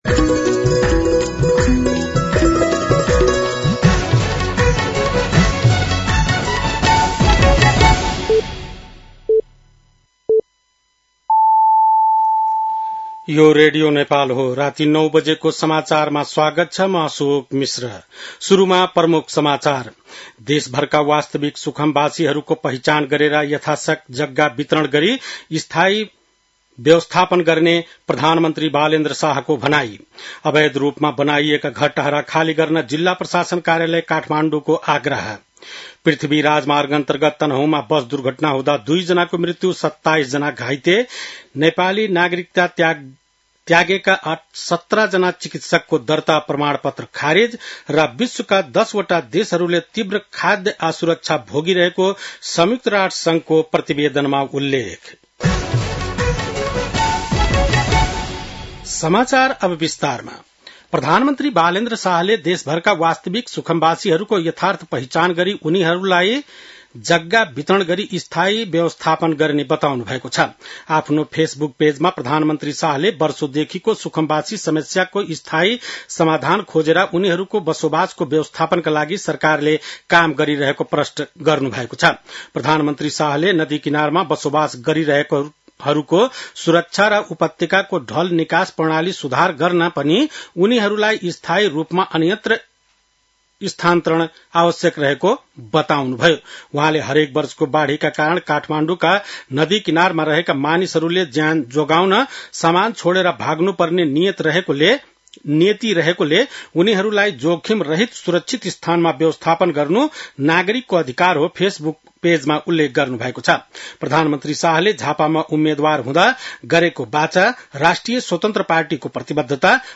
बेलुकी ९ बजेको नेपाली समाचार : ११ वैशाख , २०८३
9-PM-Nepali-NEWS-01-11.mp3